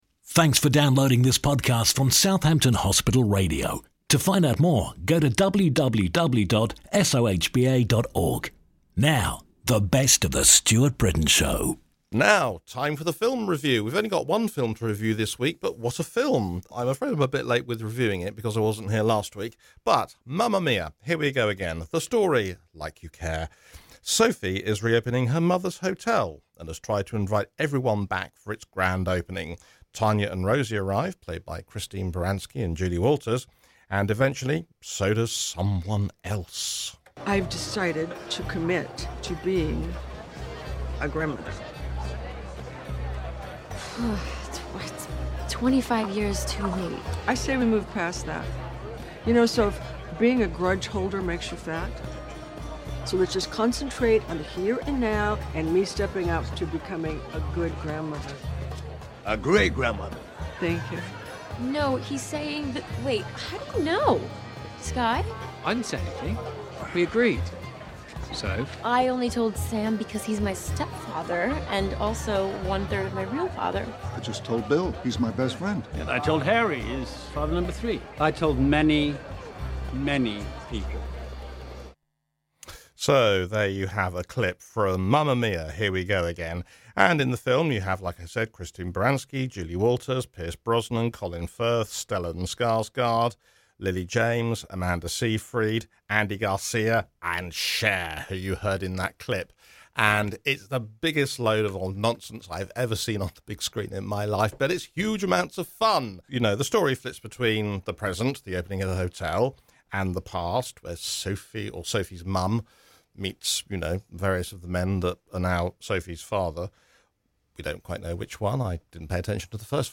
Mamma Mia! Here We Go again film clip courtesy Universal Pictures International UK